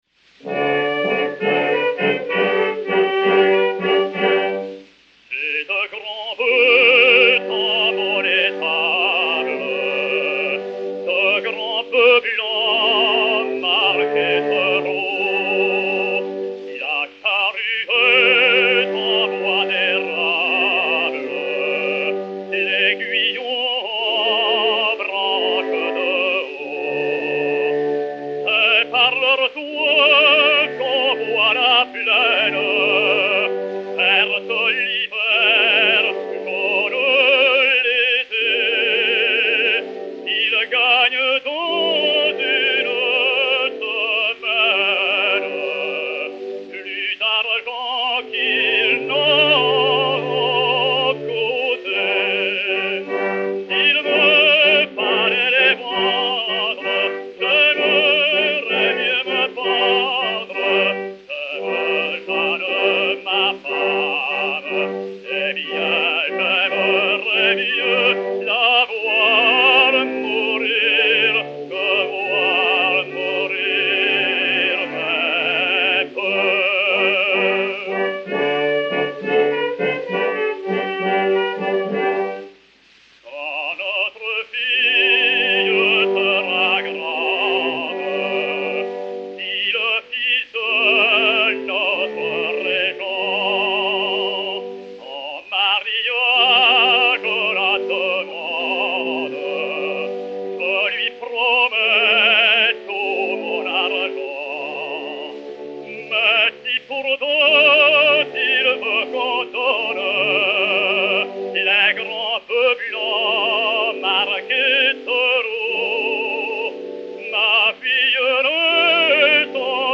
basse française
Orchestre